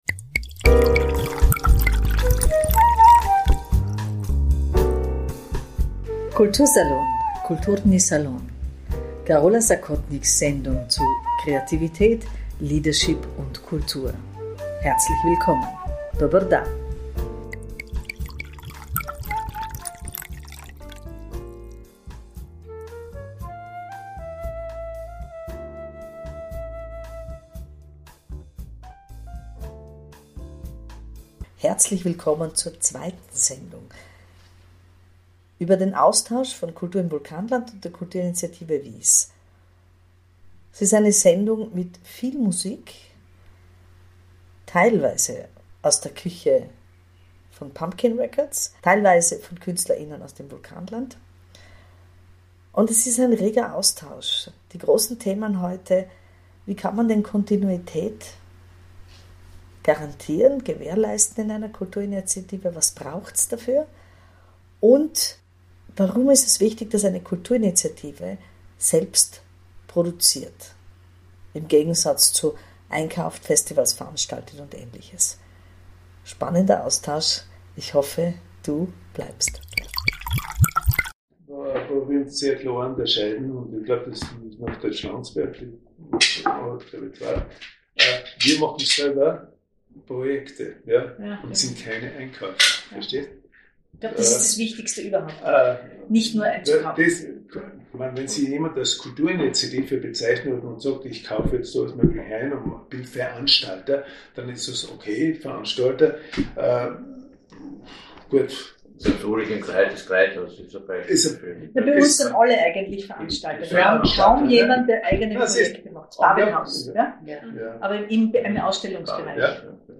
Das anregende Gespräch geht weiter und handelt davon, wie im Kürbis Kontinuität seit 50 Jahren gelingt. Kultur im Vulkanland gestaltet sich dabei grundlegend anders, ist es doch eine Plattform, die der Vernetzung dient und die Synergien herstellt, vor allem was den Außenauftritt angeht.